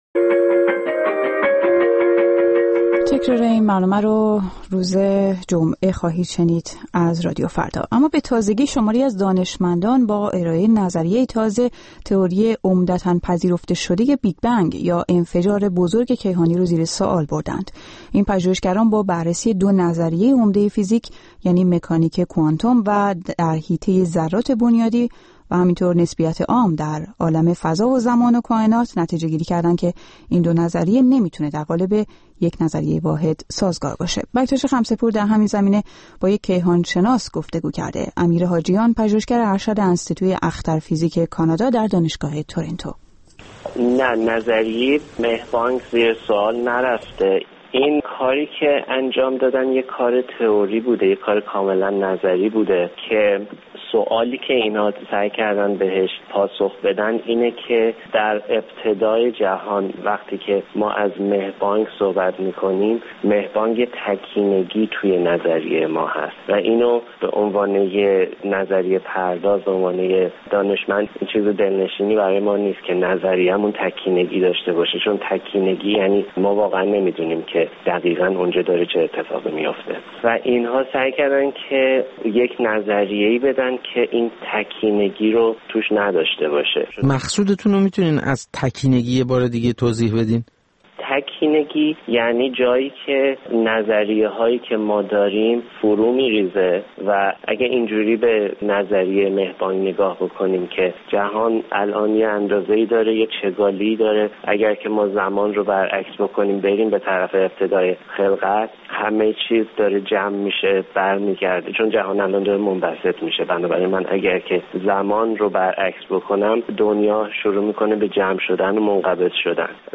مصاحبه با رادیو فردا